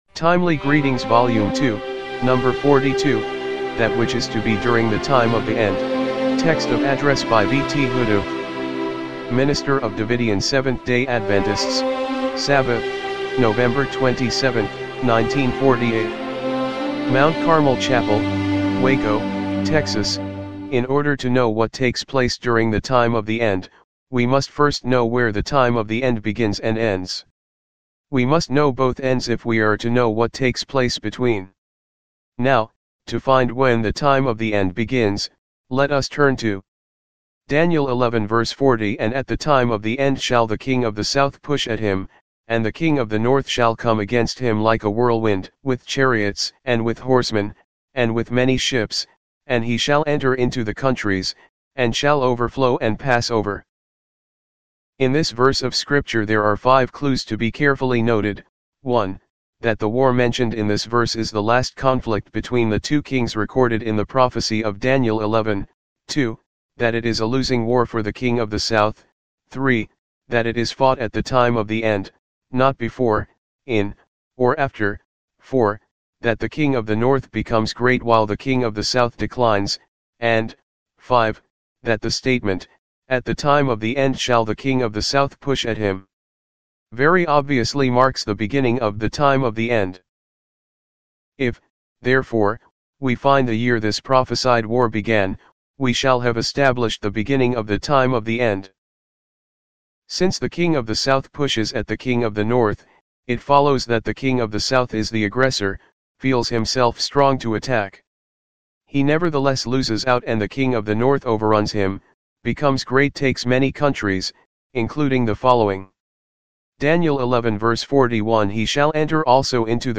TEXT OF ADDRESS